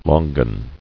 [lon·gan]